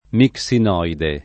mixinoide [ mik S in 0 ide ]